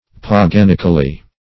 Meaning of paganically. paganically synonyms, pronunciation, spelling and more from Free Dictionary.
paganically.mp3